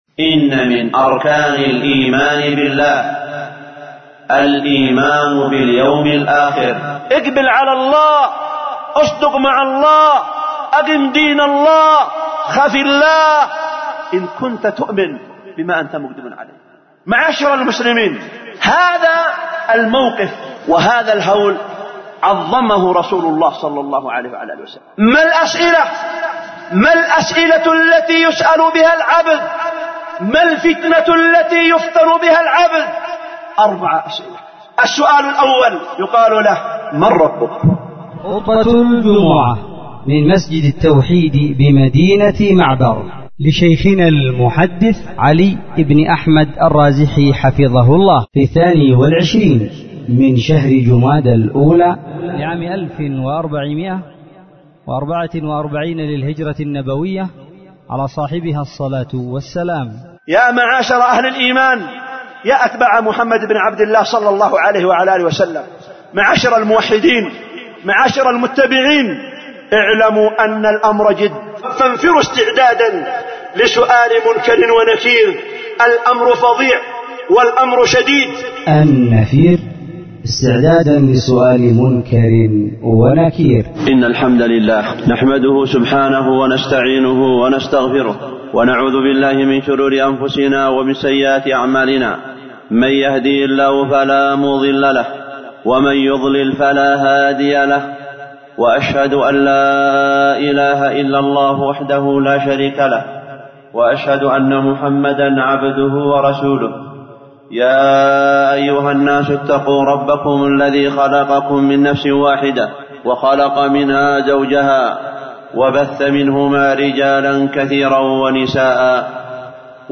خطبة جمعة